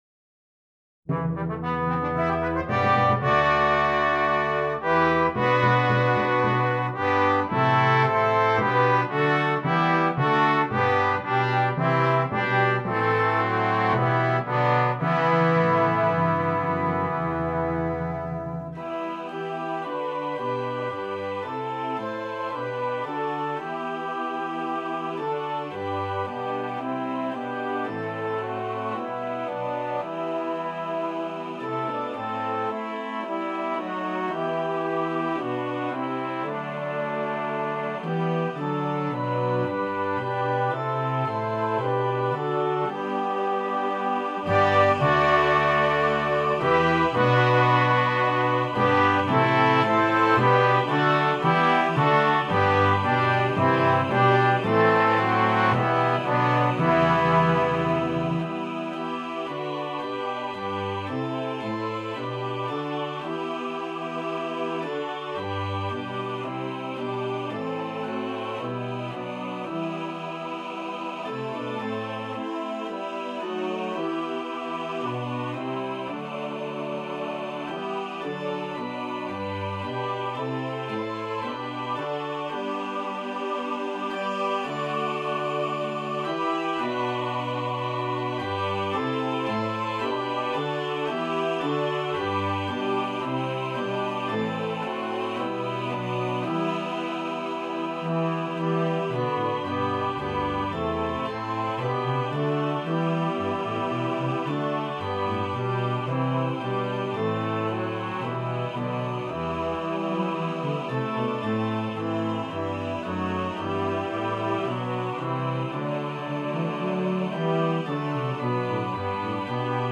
Christmas
Brass Quintet, Organ and Optional Choir
Traditional